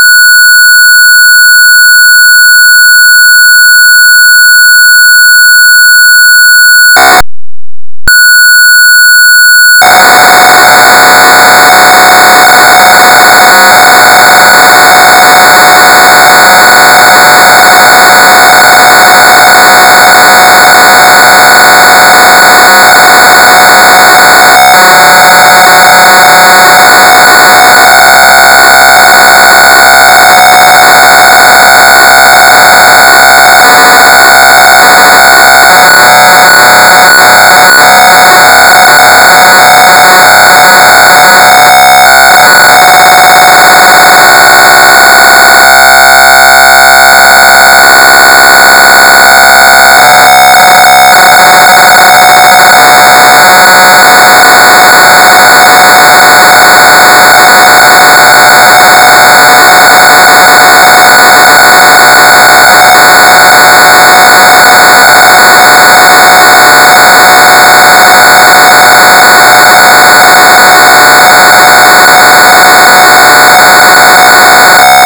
ПК-01 Львов WEB Tape Loader